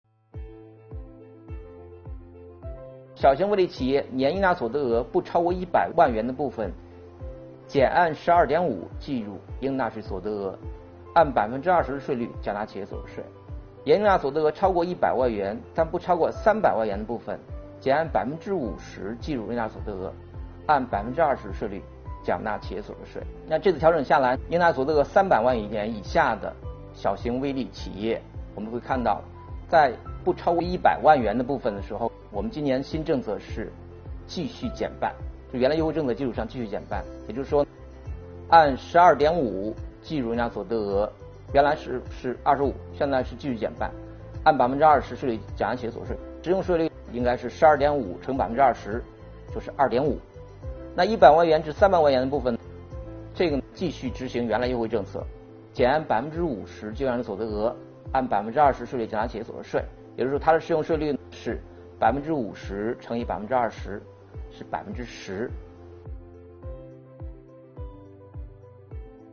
近日，国家税务总局推出最新一期“税务讲堂”课程，税务总局所得税司副司长王海勇介绍并解读了小型微利企业和个体工商户所得税优惠政策。